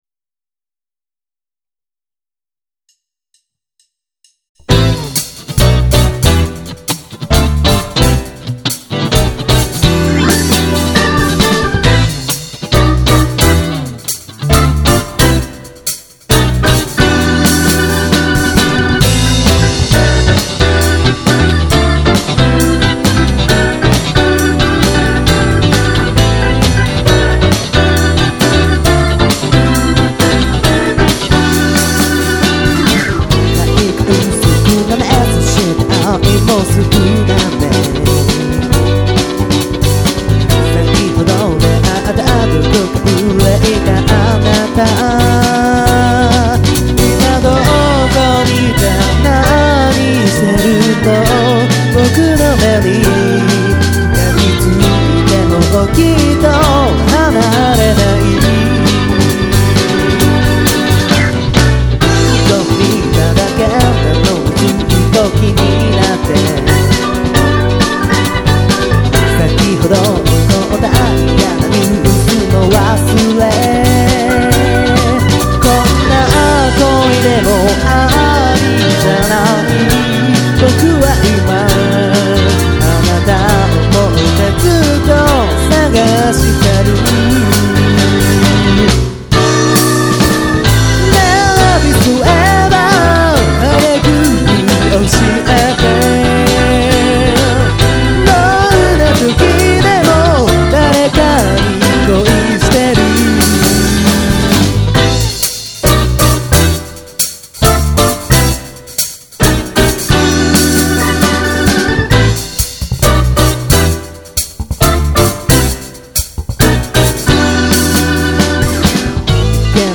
【vocal&guitar】
【guitar】
【bass】
【drum】